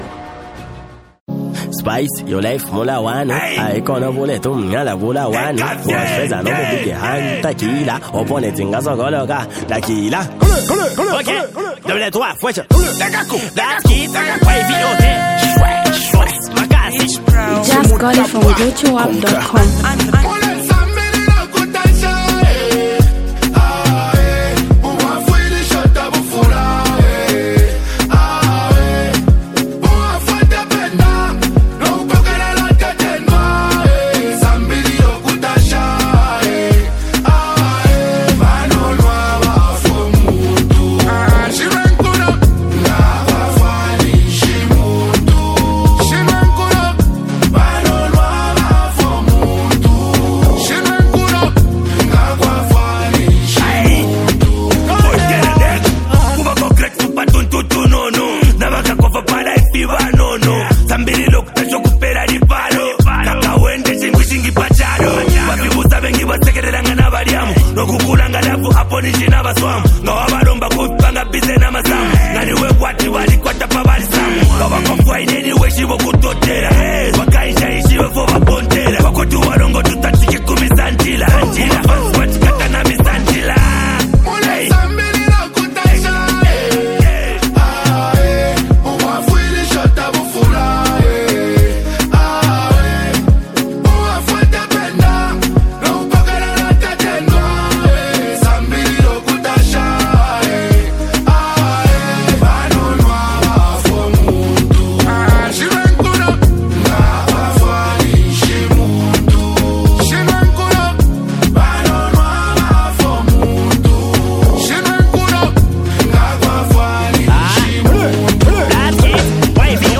Zambian uprising talented rap sensation artist.